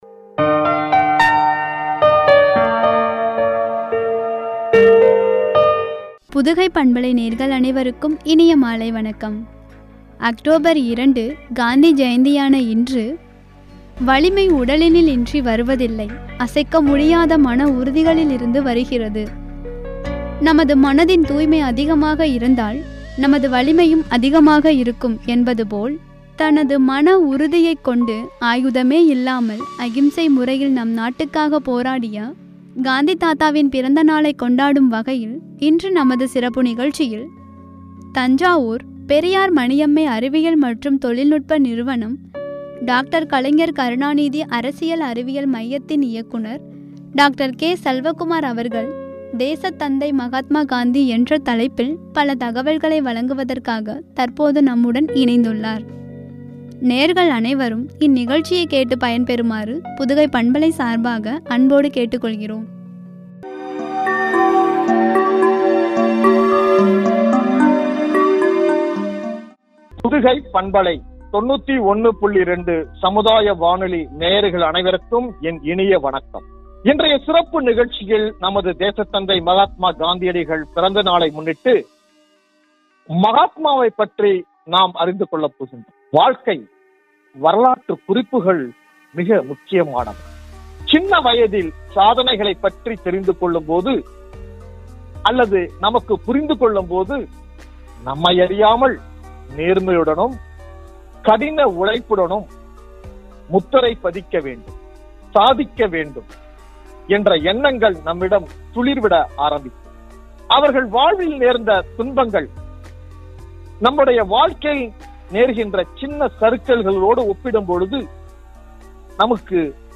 வழங்கிய உரை